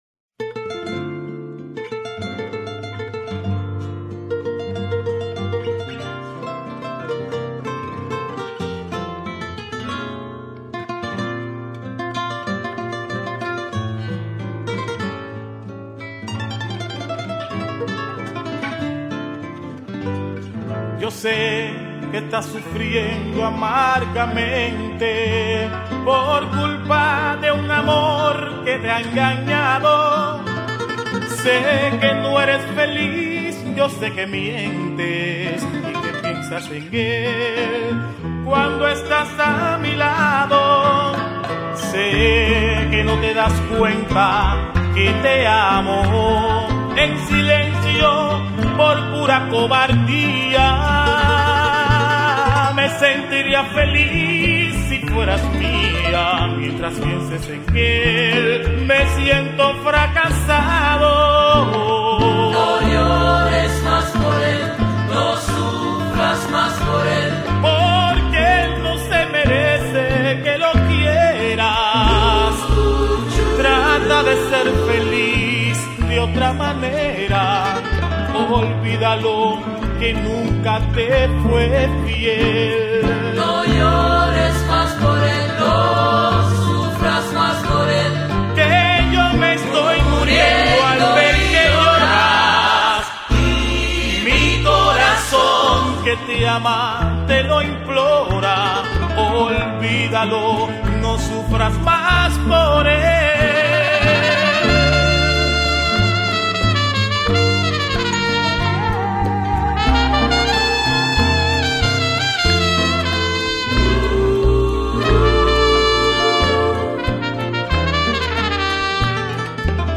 Вернее, первая часть – заставляет.
Какой там бэквокал!!!